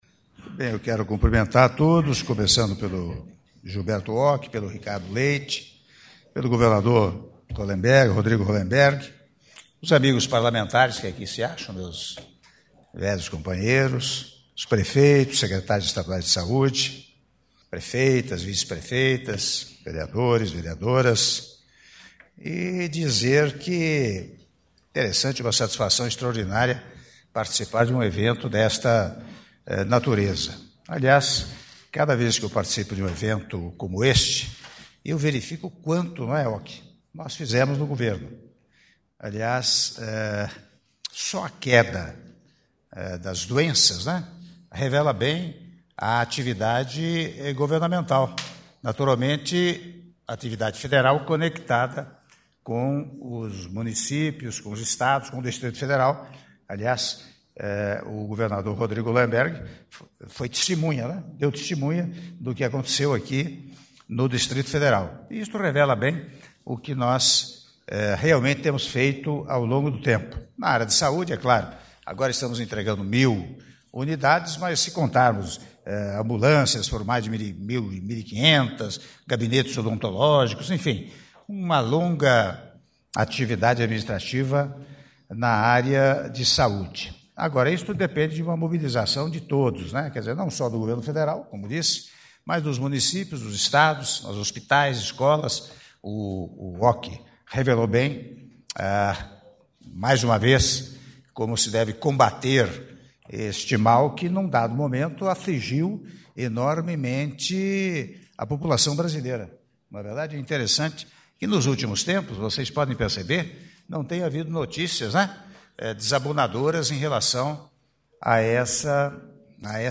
Áudio do discurso do Presidente da República, Michel Temer, durante Cerimônia de Anúncio da aquisição de 1.000 camionetes pelo Ministério da Saúde para municípios da federação para o controle e o combate às endemias - Palácio do Planalto (03min35s) — Biblioteca